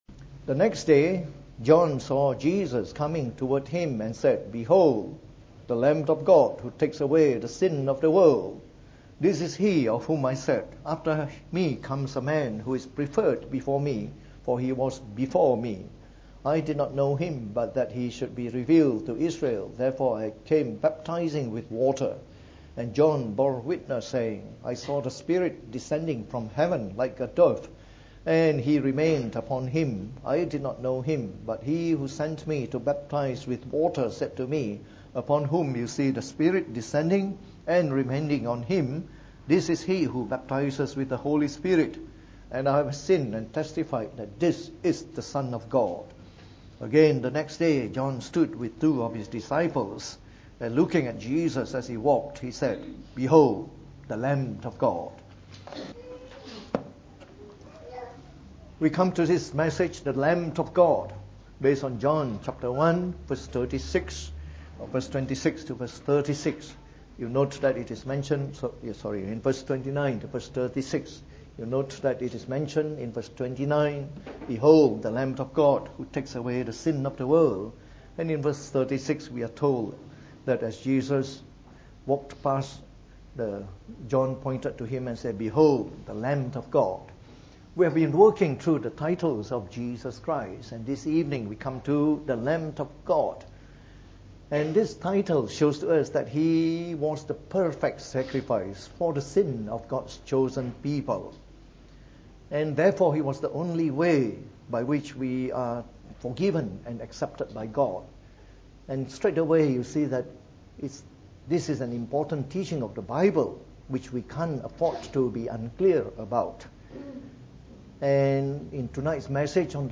From our series on the Titles of Jesus Christ delivered in the Evening Service.